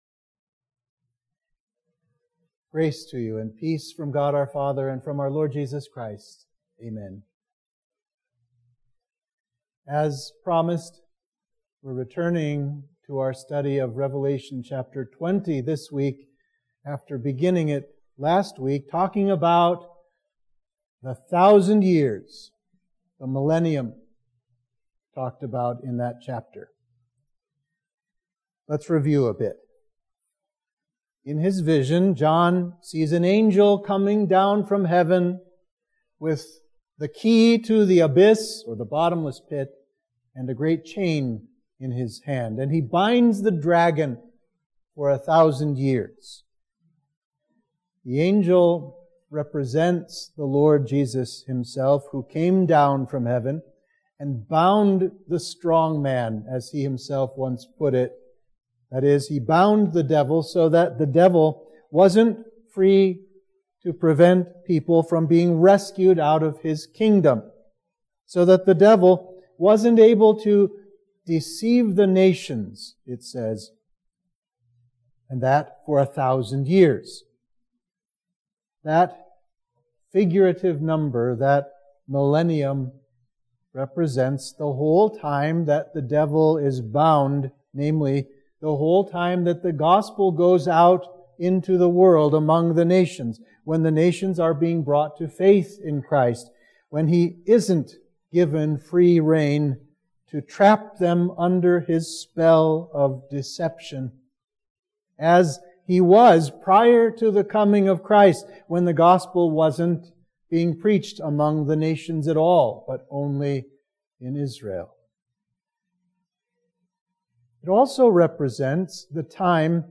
Sermon for Midweek of Trinity 19